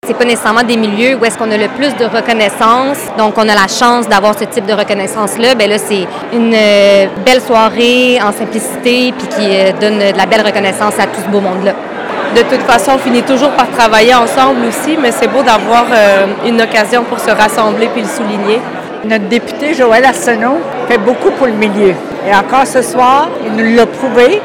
L’engagement de nombreux organismes madelinots a été souligné lors d’une première soirée de reconnaissance qui s’est tenue, jeudi soir, à Cap-aux-Meules.
Certaines personnes présentes ont raconté ce qu’elles retiennent de la soirée.